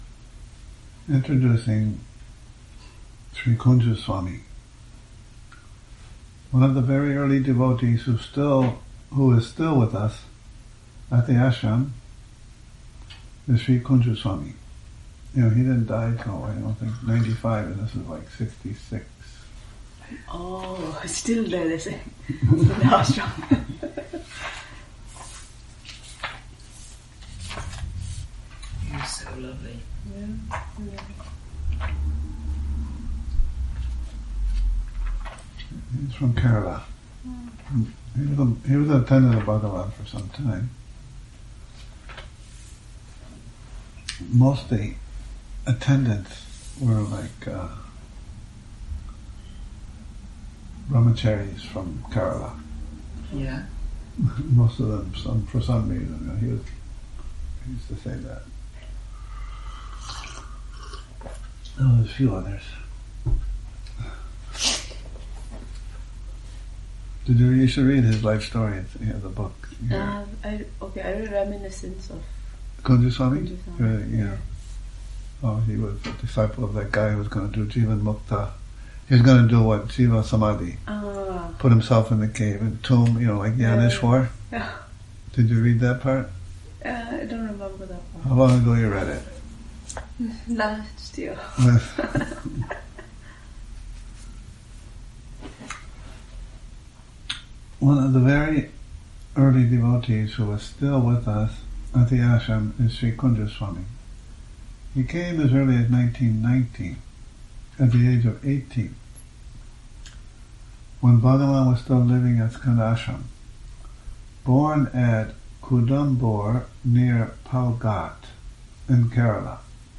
Morning Reading, 13 Nov 2019